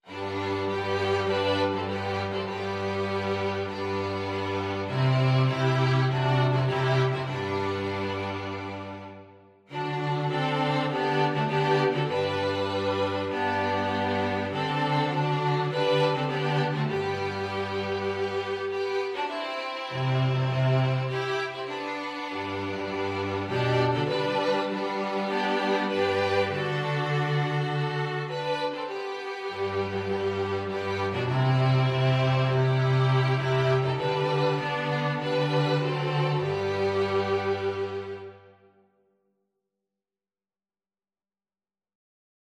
Violin 1Violin 2ViolaCello
4/4 (View more 4/4 Music)
Classical (View more Classical String Quartet Music)